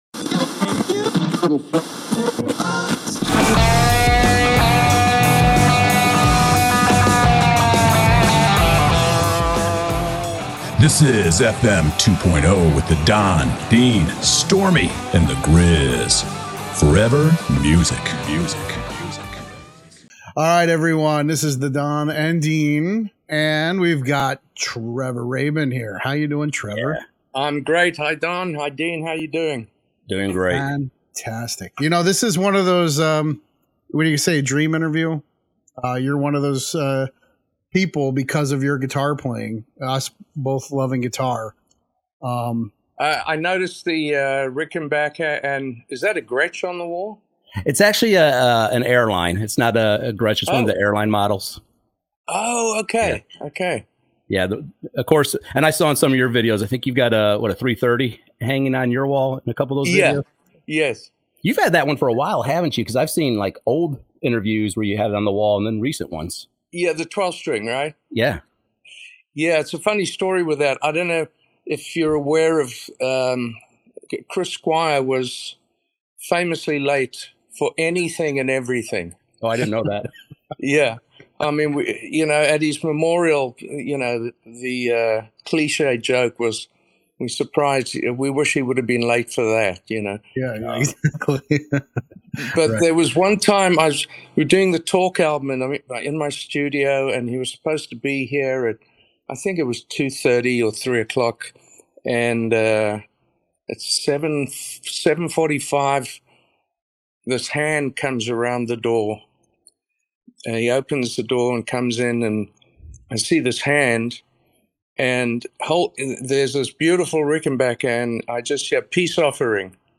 Iconic Guitarist Trevor Rabin Interview: From YES to RIO in 34 Years: FM 2.0 Conversations